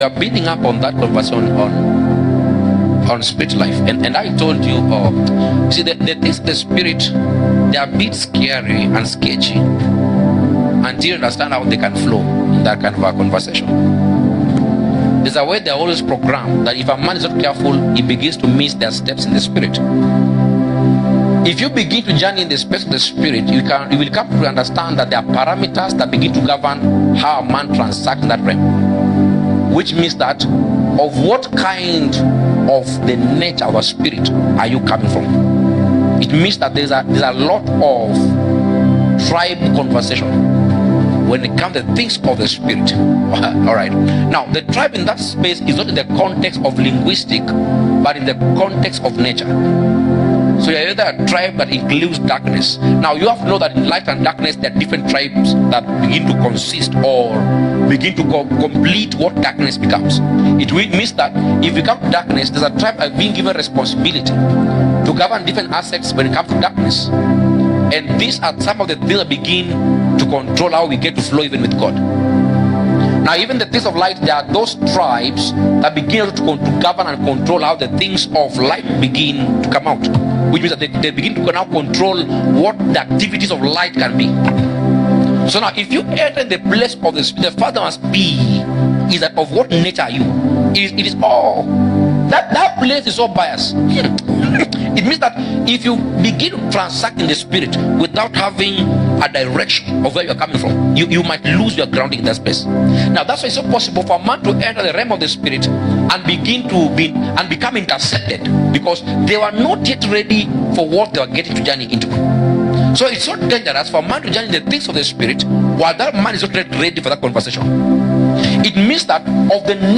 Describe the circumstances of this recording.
Sunday-Service-8th-June-2025.mp3